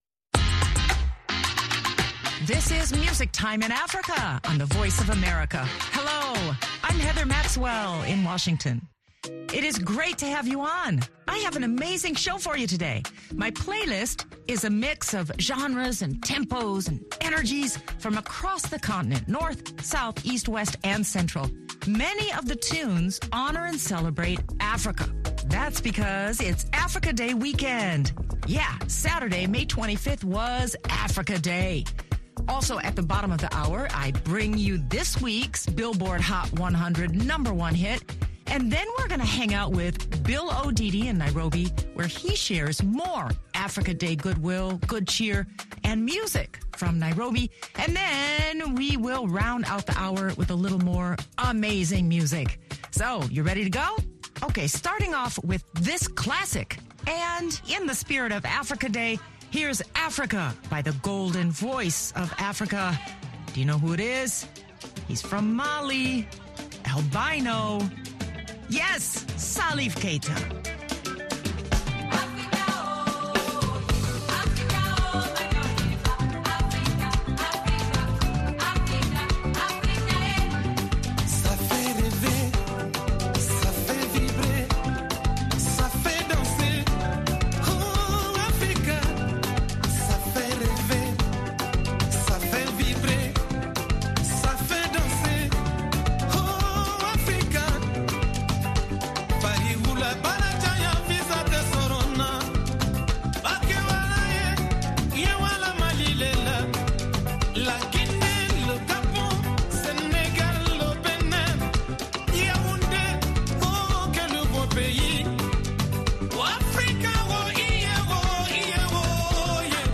Music Time in Africa is VOA’s longest running English language program. Since 1965 this award-winning program has featured pan African music that spans all genres and generations.